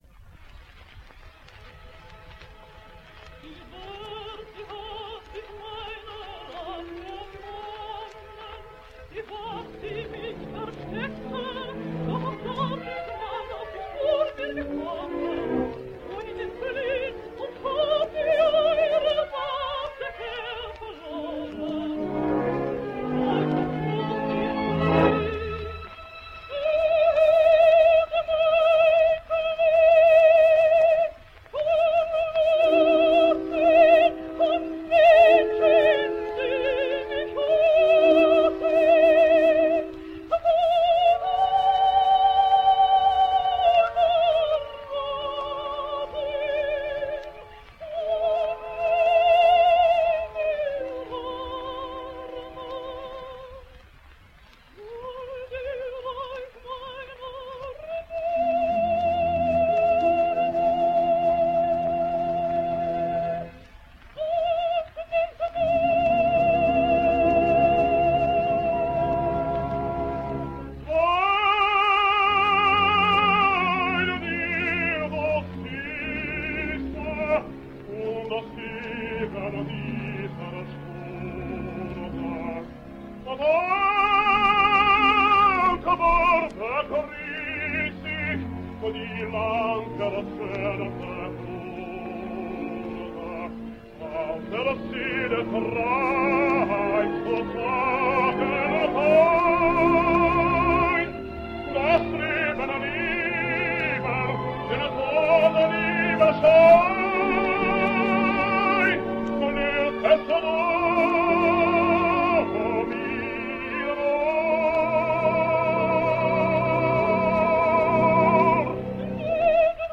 Italian Tenor
Duet w Seinemeyer / Chenier / 1926 – Tino Pattiera